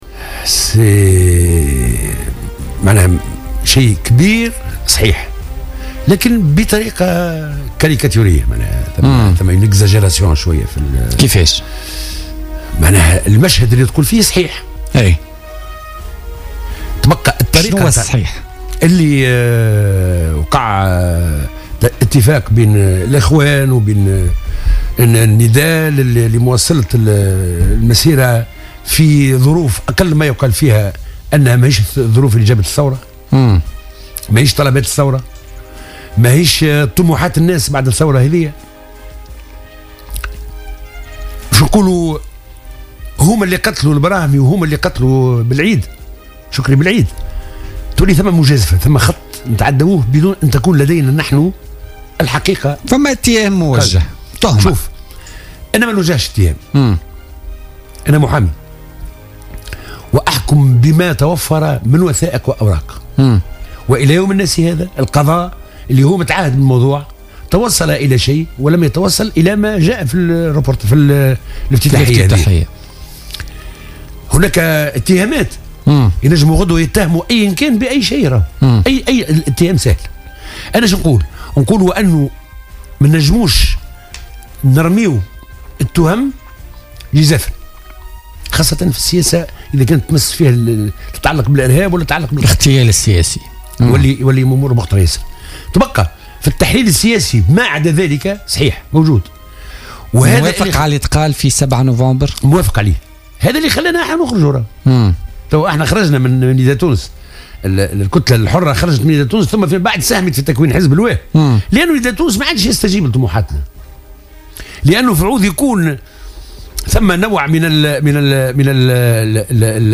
أكد النائب عن الكتلة الحرة عبادة الكافي ضيف بوليتيكا اليوم الثلاثاء 8 نوفمبر 2016...